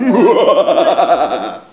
rire-machiavelique_04.wav